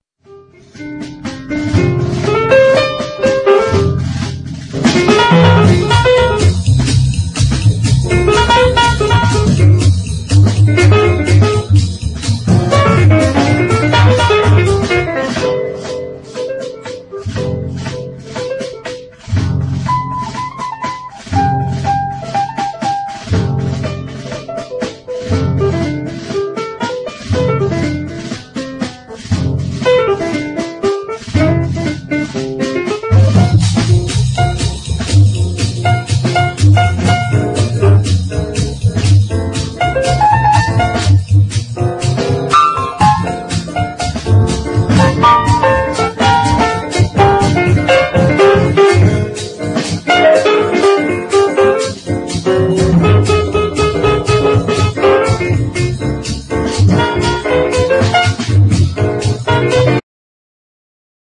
JAZZ / MAIN STREAM / CHRISTMAS
モダンでソウルフルに仕上げられた